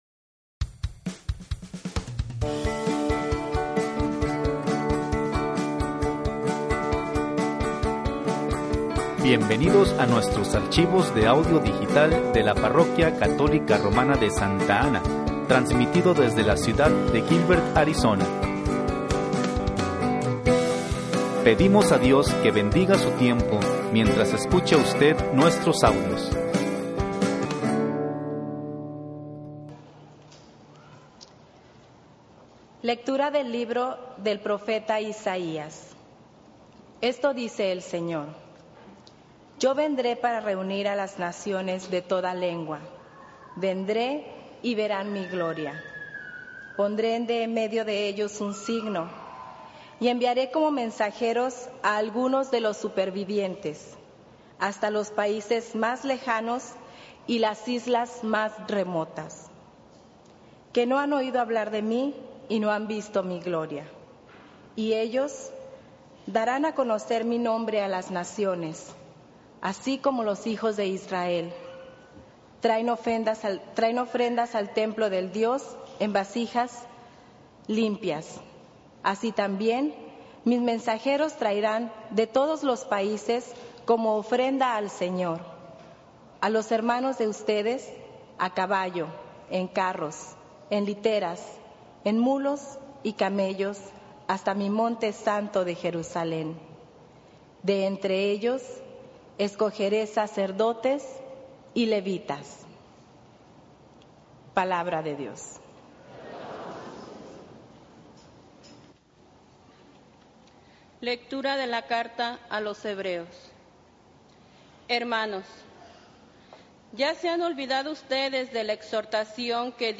XXI Domingo Tiempo Ordinario (Lecturas)
Evangelio, Lecturas, Tiempo Ordinario